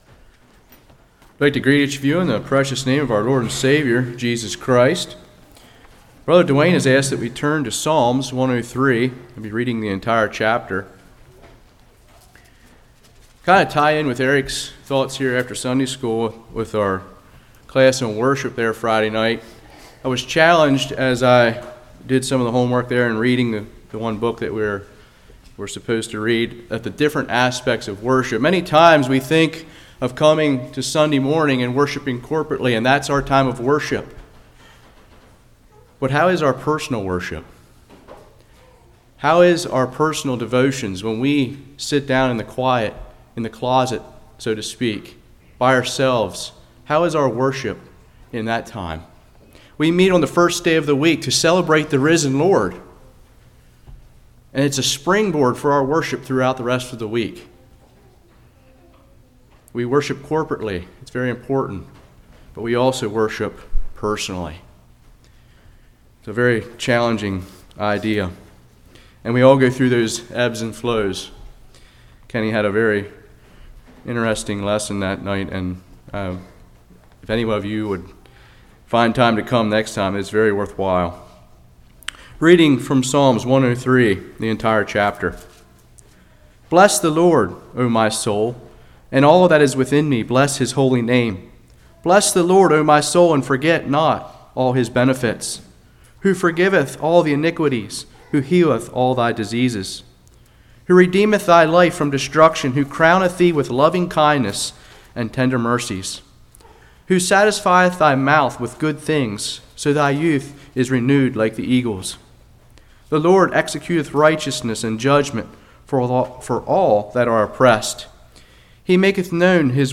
Psalms 103 Service Type: Morning Why doesn’t God always heal?